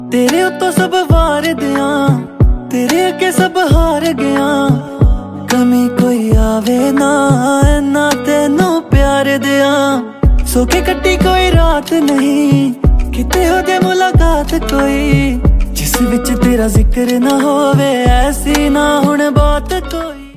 a romantic Punjabi song
Punjabi Songs